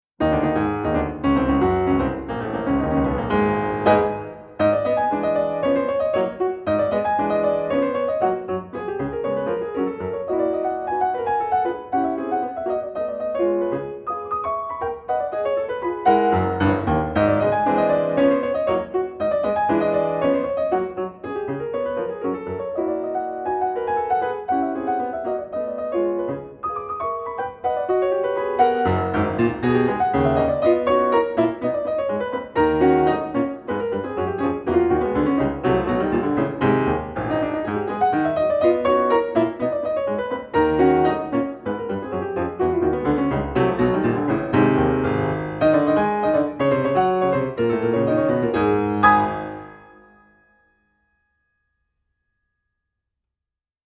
piano piece